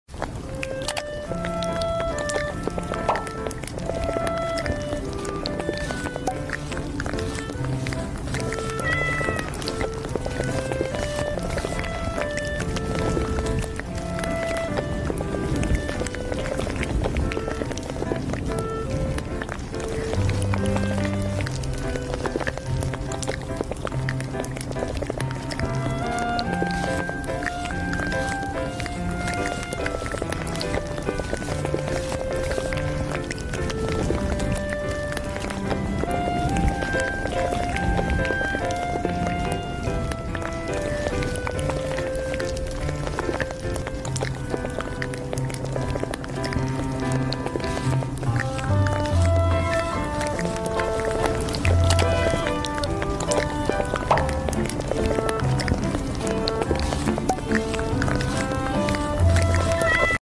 cafe sound !! sound effects free download